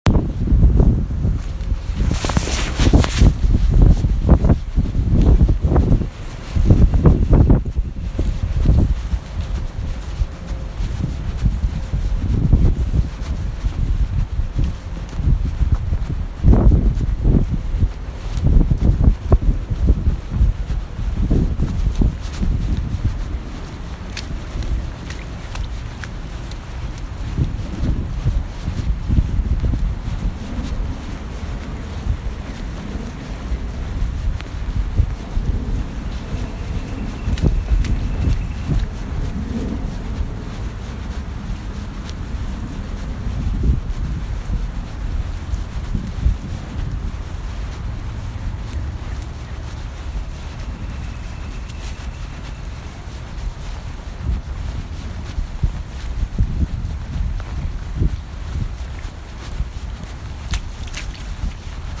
Chirping – Hofstra Drama 20 – Sound for the Theatre
Location: Outside of Vander Poel 4/7/18 around 6pm
Sounds heard: Birds chirping, my footsteps on grass, cars passing by in the Vander Poel parking lot and on Oak Street